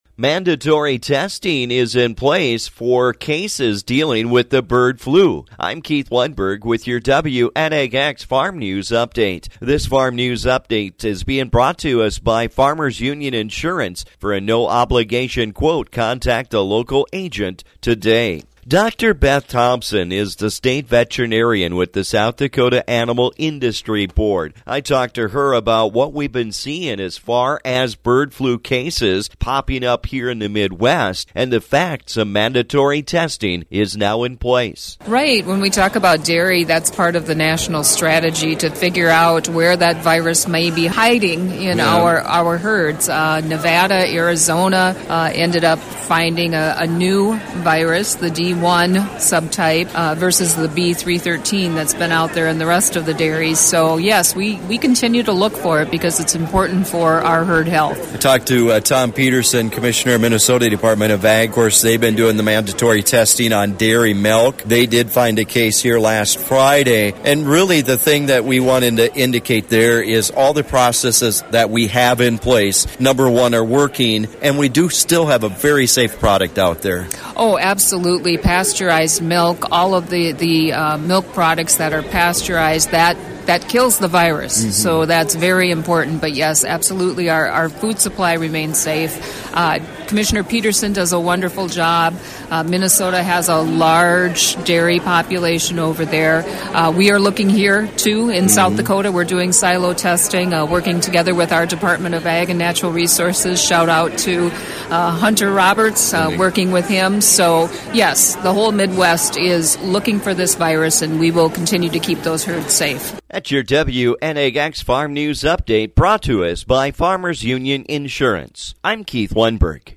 I put that question to Dr. Beth Thompson, the South Dakota State Veterinarian.